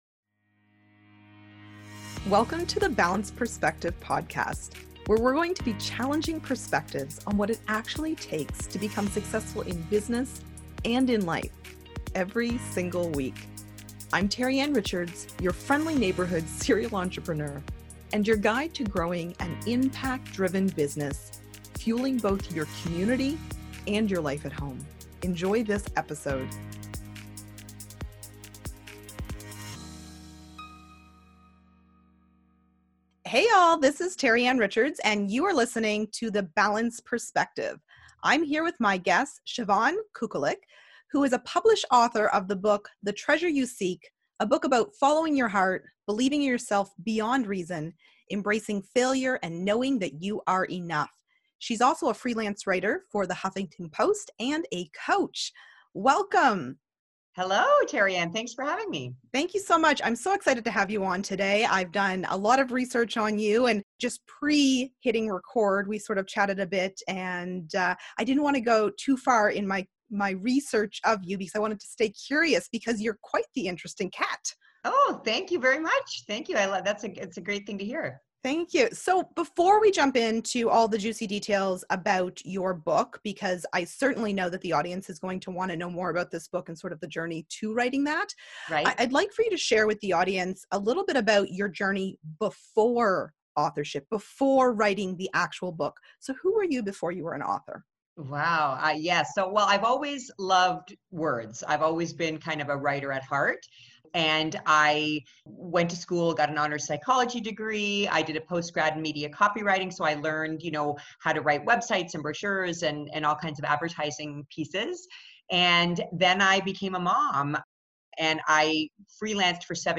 This powerful conversation is easy to listen to and spans some of the biggest concepts that impact the level of success you may (or may not) be experiencing right now.